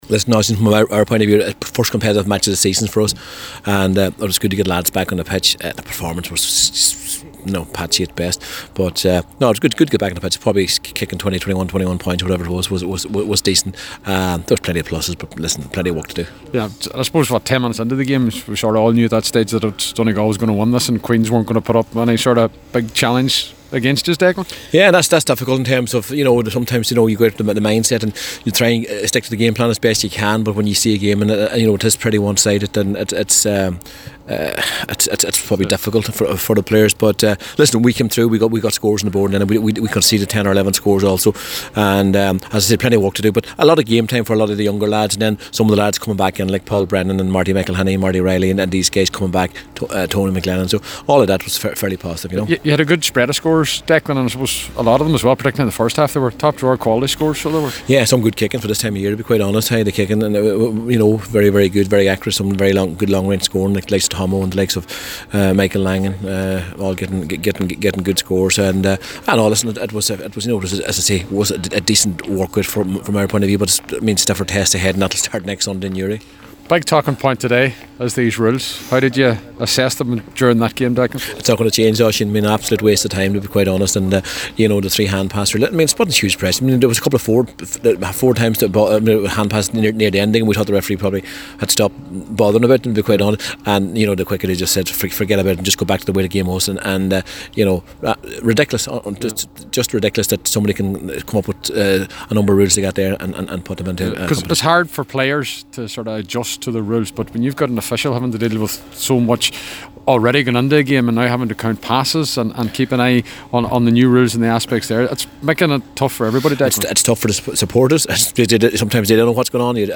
Donegal ease to victory over Queens: Report & Reaction
After the game Declan Bonner said it was good to get back playing but also highlighted confusion with the rules and how non beneficial they are…